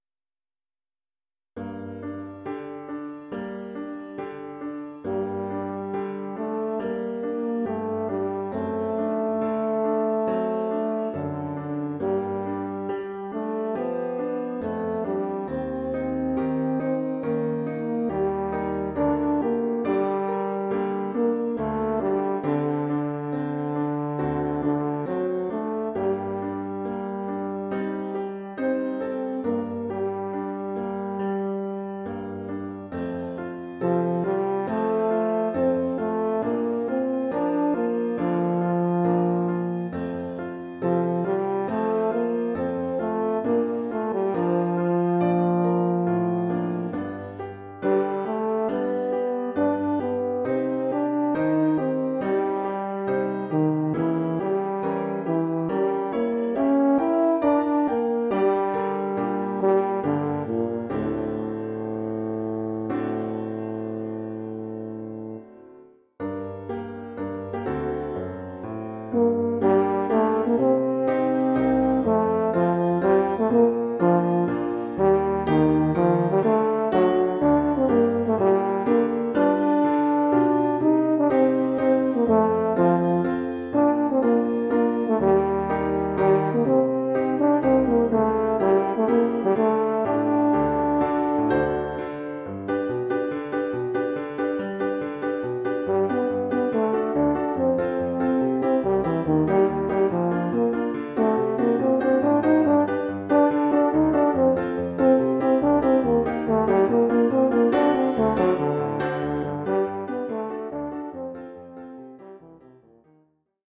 Oeuvre pour saxhorn basse et piano.
Oeuvre pour saxhorn basse / euphonium /
tuba et piano.